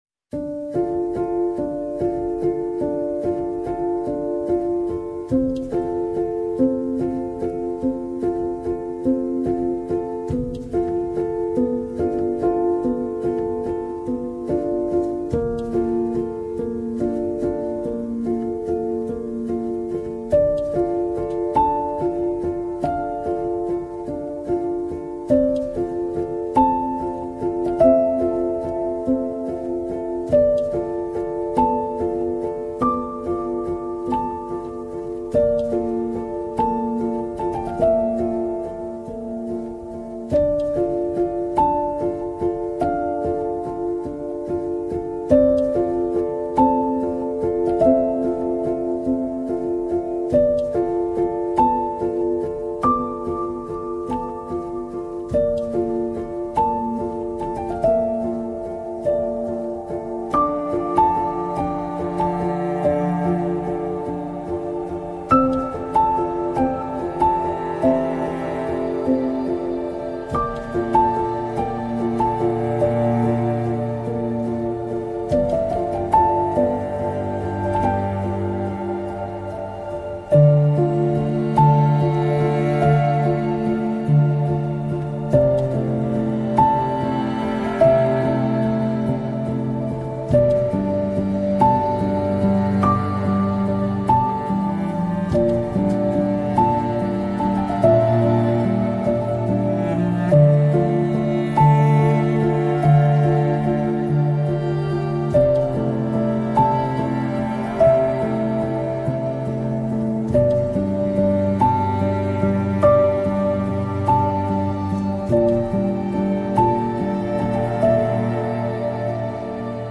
Classical Orchestral Melody